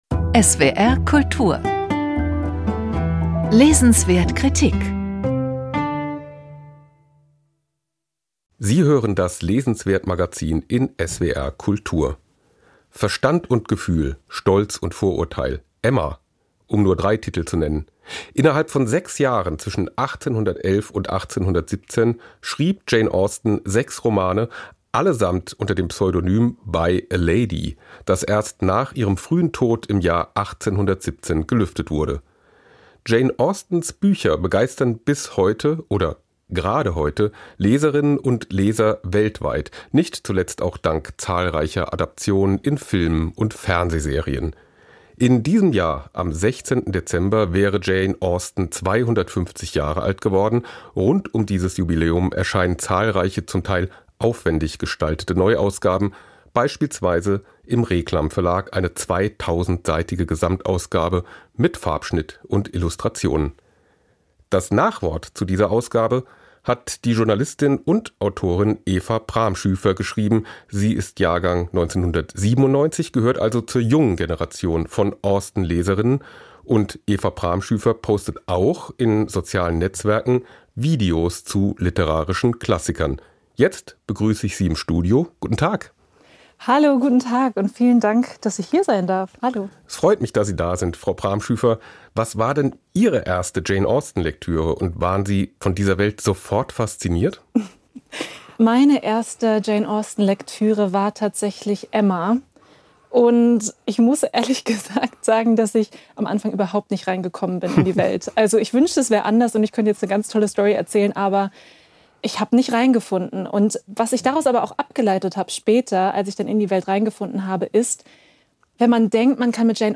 250-geburtstag-jane-austen-gespraech.mp3